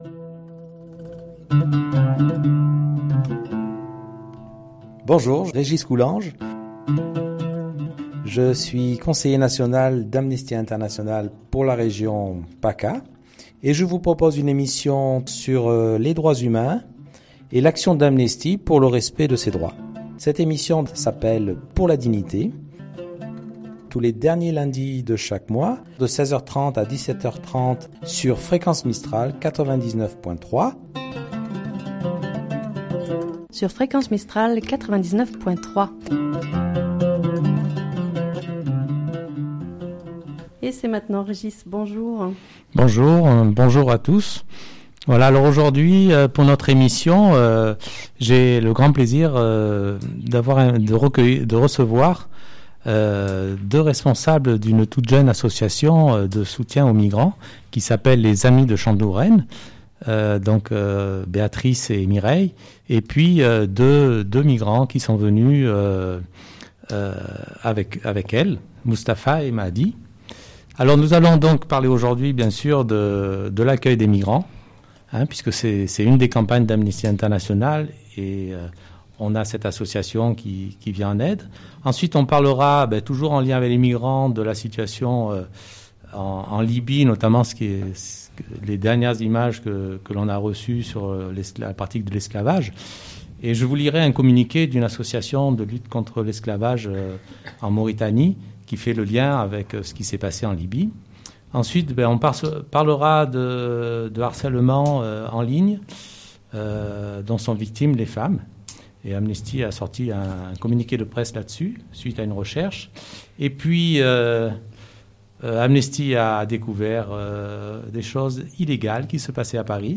4 invités aujourd’hui